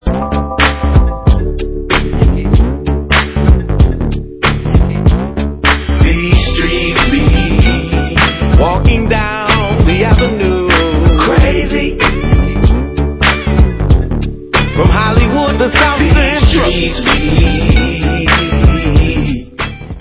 Dance/Hip Hop